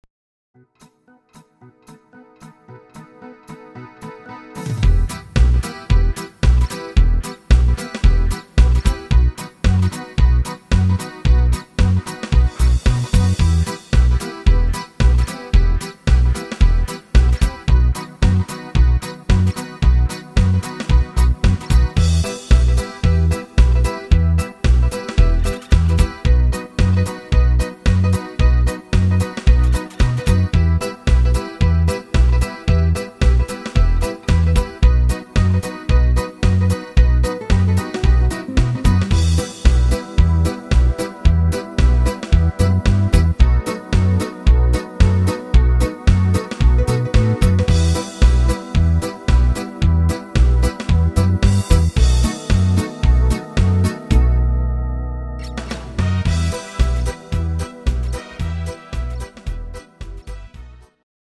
Buy -- Playback abmischen Buy